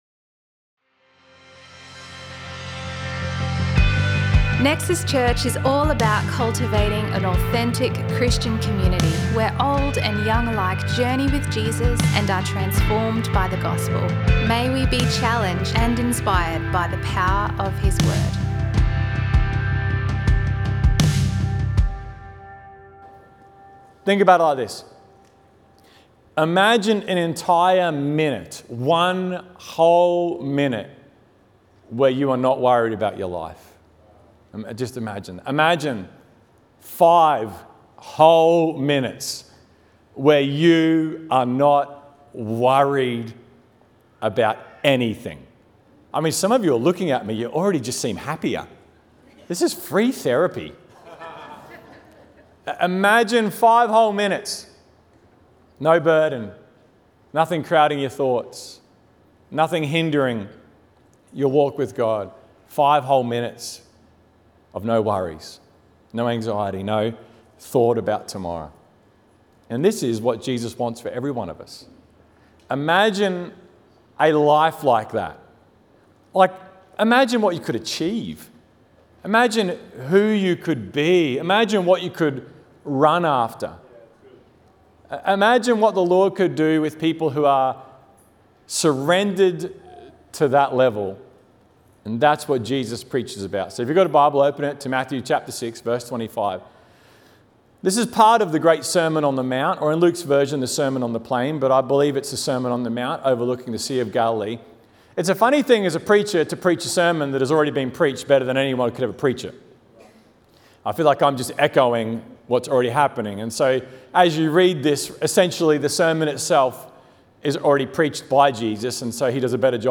A selection of messages from Nexus Church in Brisbane, Australia.